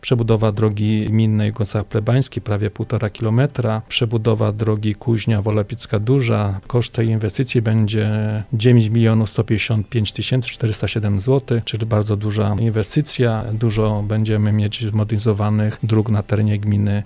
O szczegółach, burmistrz Jastrzębia Andrzej Bracha: